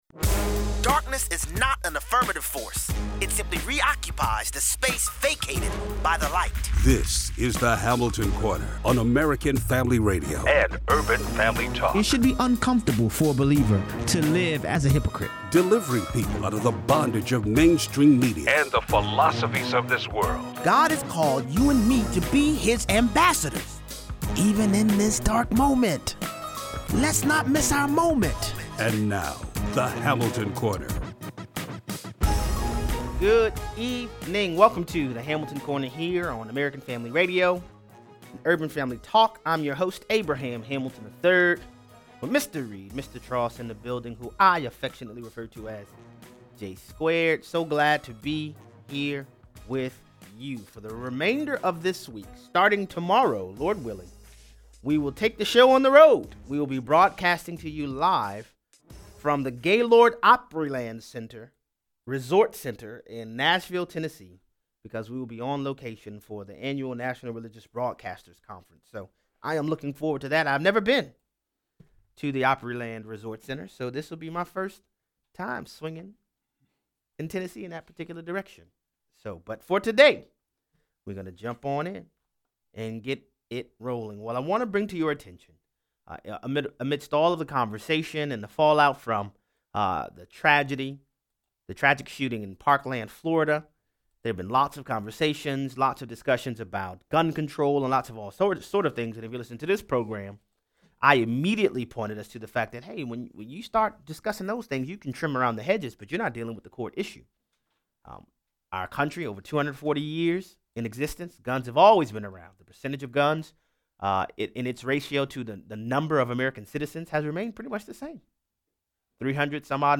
Captivity is the only result when any people reject the LORD and lean of humanistic ideology. 0:23 - 0:40: Mark Levin begins his Fox News Channel program by interviewing Dr. Walter Williams. Freedom doesn’t exist in a vacuum.
Callers weigh in.